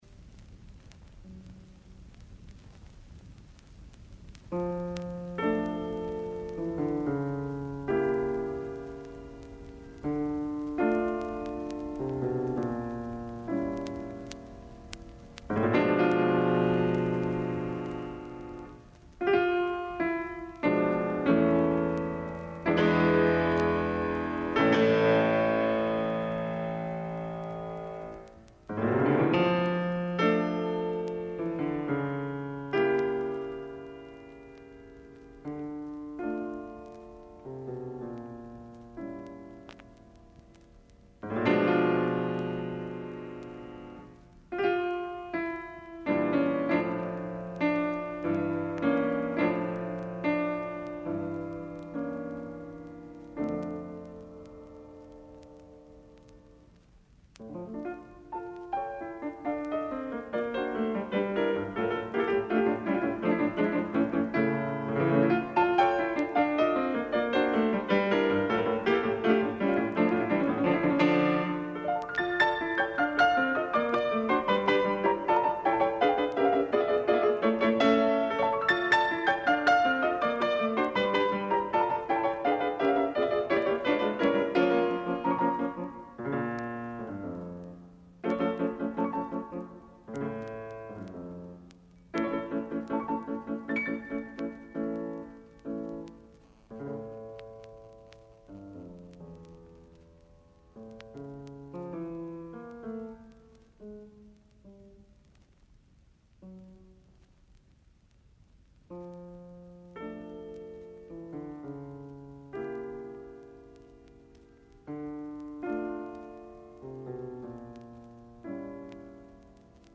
Клавирски дуо